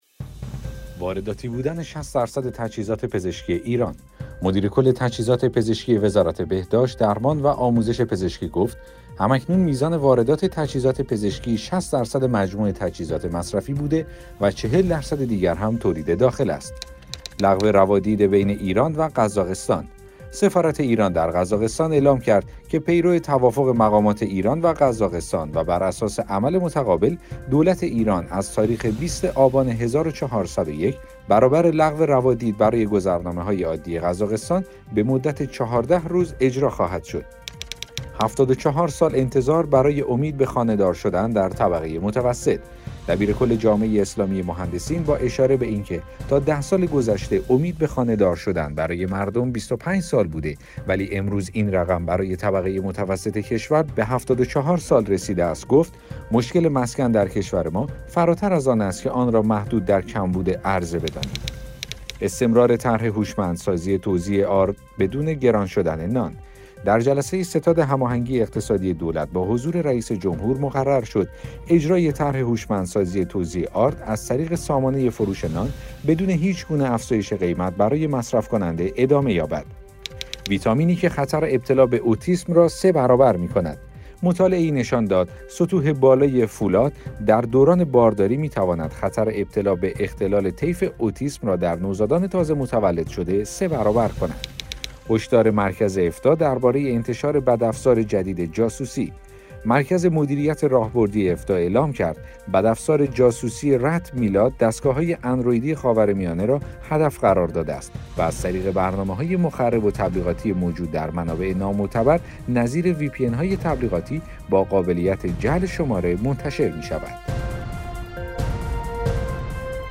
اخبار صوتی - چهارشنبه شب ۲۷ مهر ۱۴۰۱